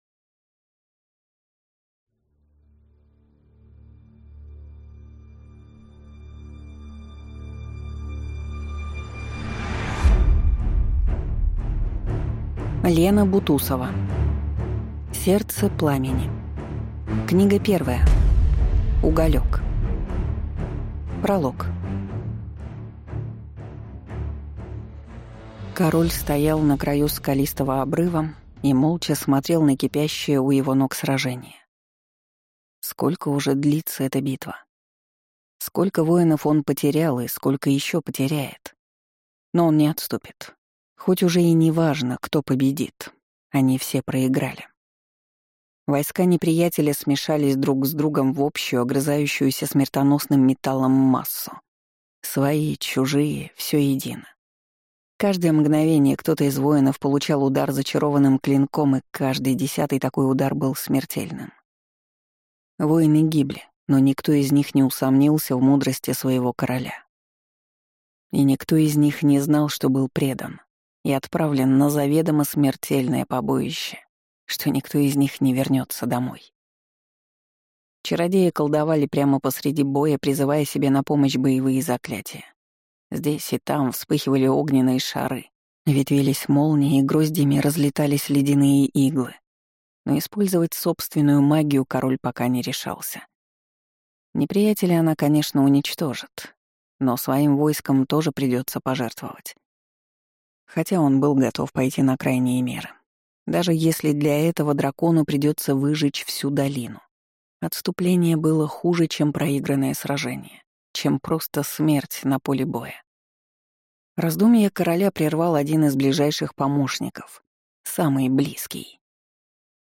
Аудиокнига Сердце Пламени. Книга 1. Уголёк | Библиотека аудиокниг